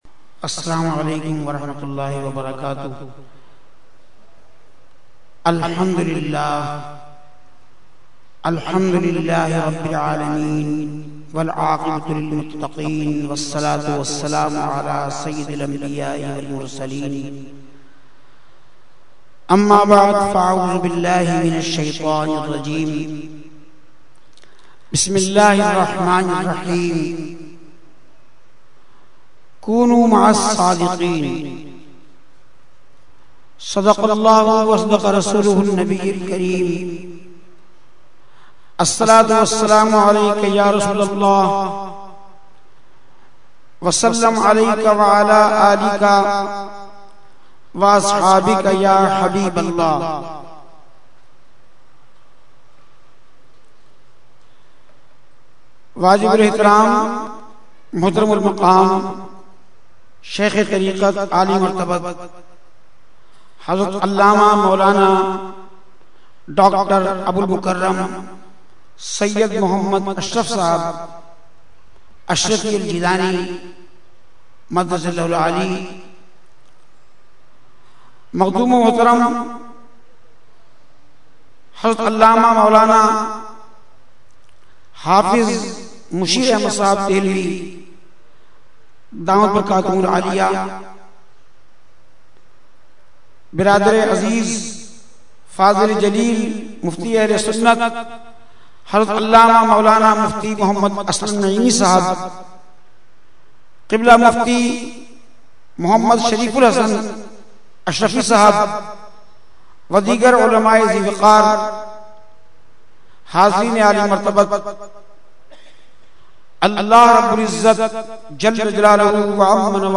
Category : Speech | Language : UrduEvent : Urs Qutbe Rabbani 2012